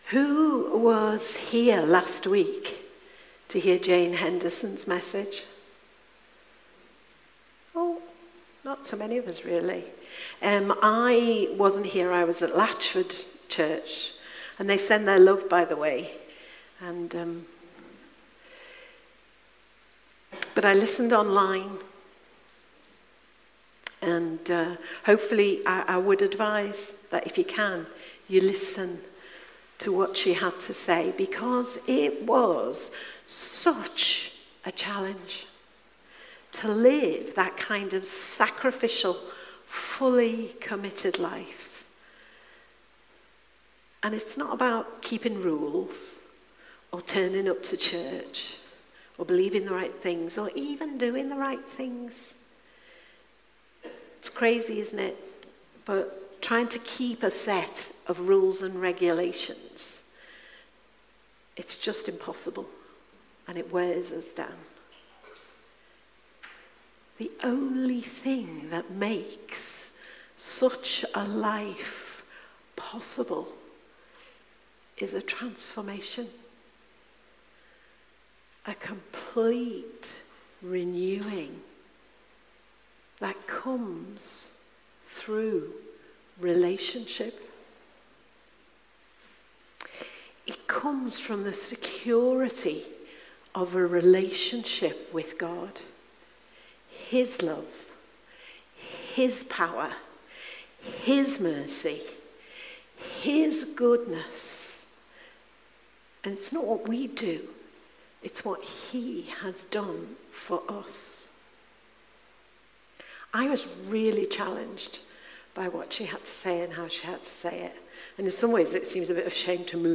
Passage: Genesis 2:4–9 Service Type: Sunday Morning